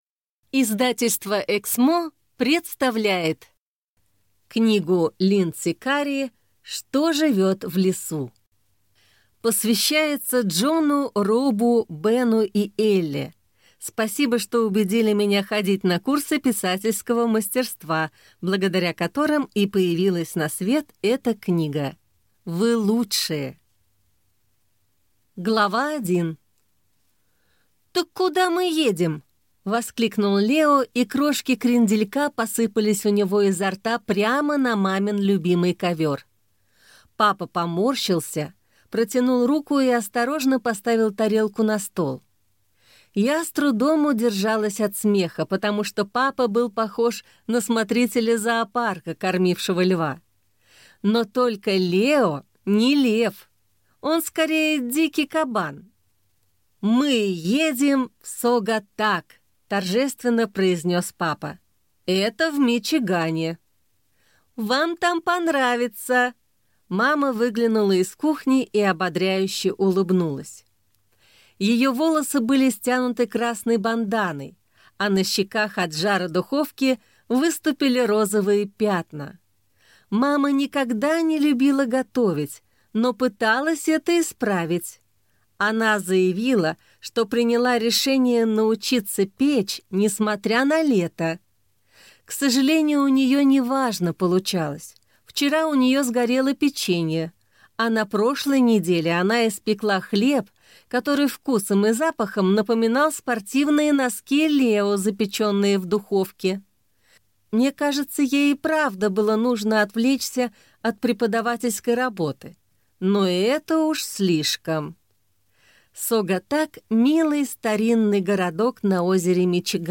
Аудиокнига Что живёт в лесу | Библиотека аудиокниг